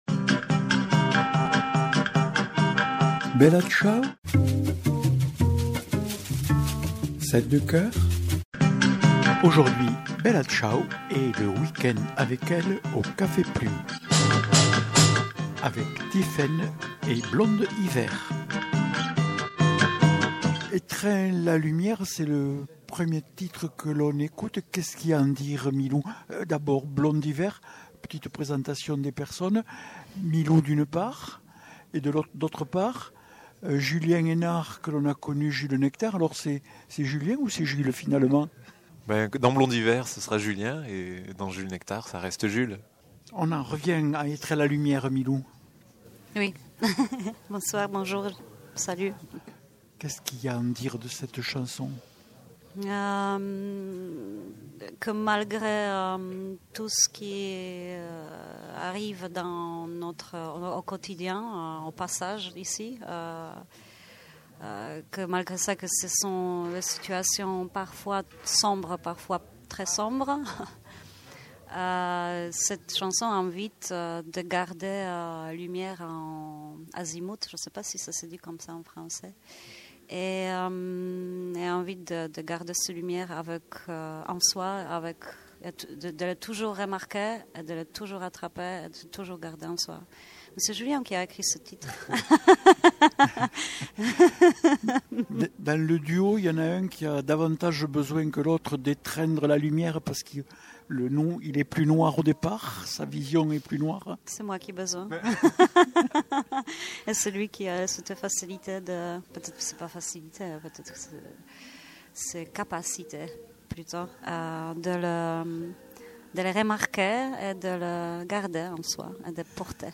Émissions
Rencontrées au Café Plum’ de Lautrec en marge de leurs concerts pour le festival un weekend avec elles.